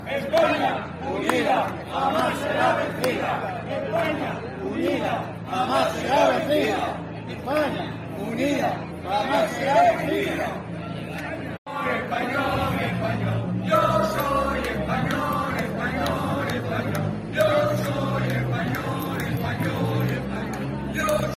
Gritos contra Pedro Sánchez y banderas de España en la primera manifestación en Lorca
Varios centenares de personas se concentran frente al ayuntamiento contra la Ley de Amnistía
Portando banderas nacionales, los manifestantes han coreado gritos como “España no se vende”, “España unida, jamás será vencida” gritos contra el presidente del Gobierno en funciones Pedro Sánchez para el que han pedido “cárcel”.